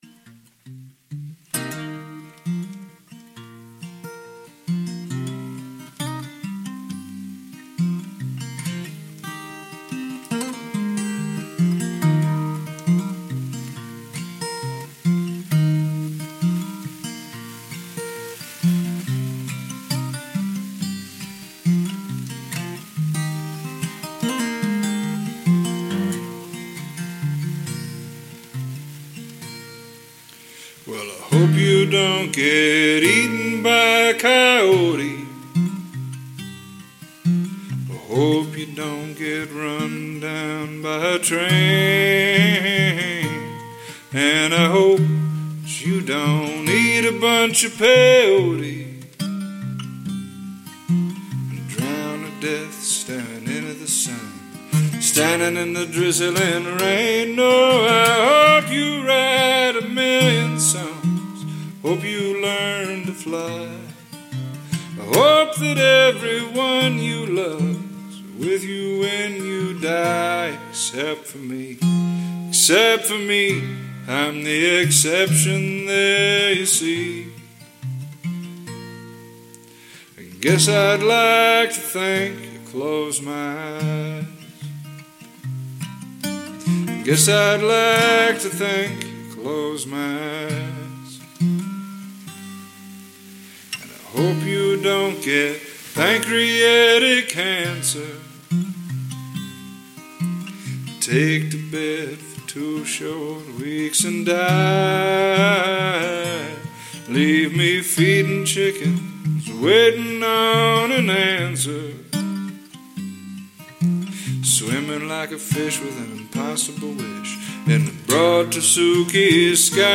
They are mostly singers.